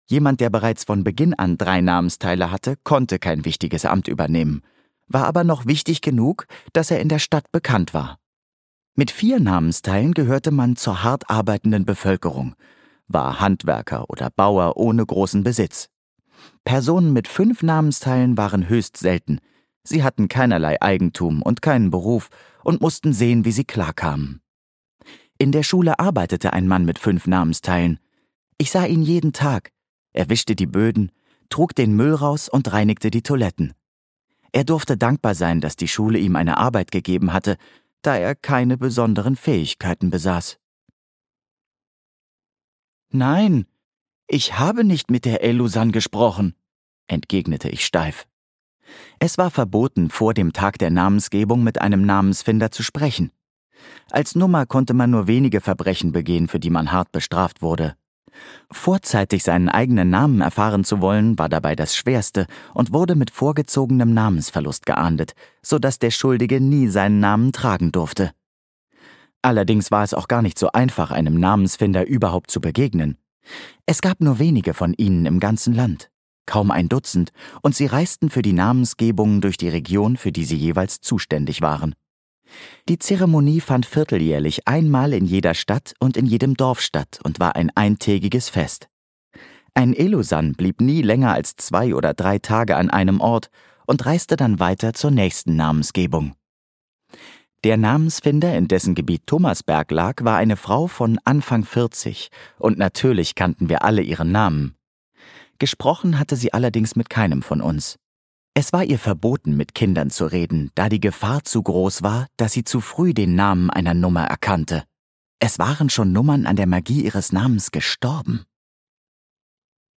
Hörbuch Die Magie der Namen, Nicole Gozdek.